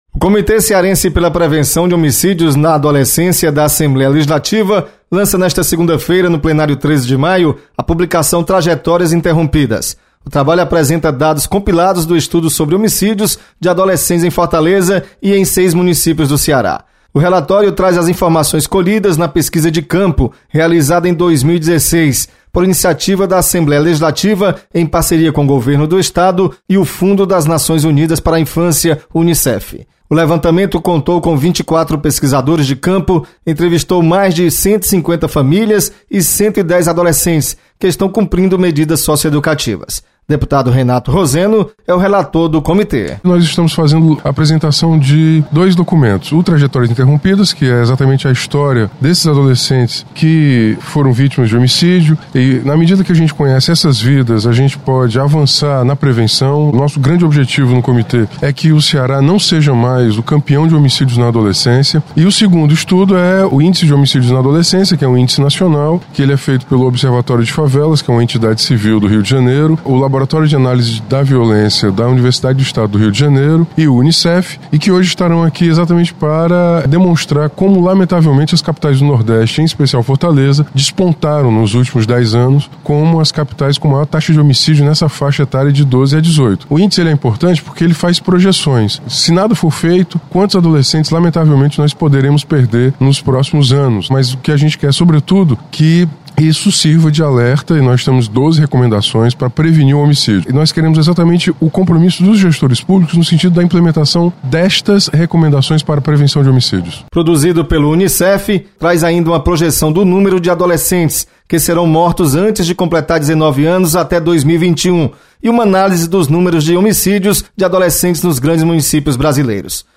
Deputado Renato Roseno destaca apresentação de relatório sobre homicídios na adolescência.